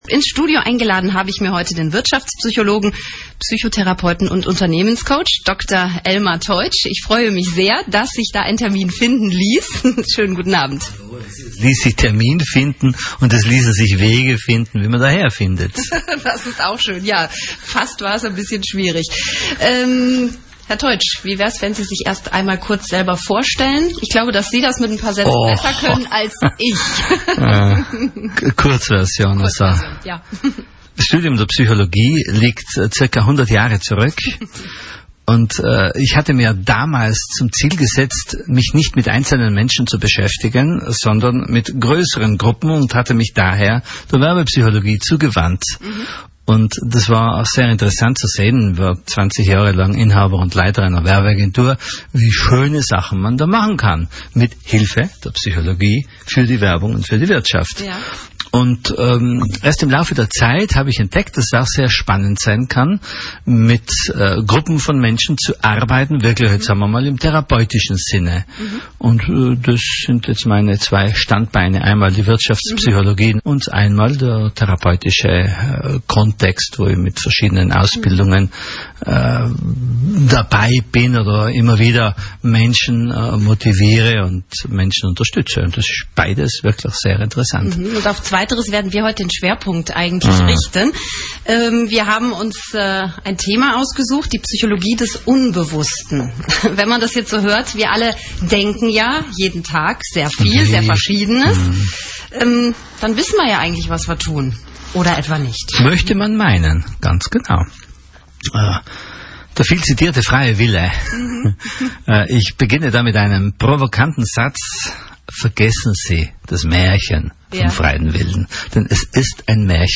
Dazu gibt es für die Hörer Tests und Übungen zum Mitmachen. Hören Sie hier Ausschnitte aus der Sendung: Komprimierte WEB-Version. Sprache und Musik, Ausschnitte, 47 Minuten, 8 MB >>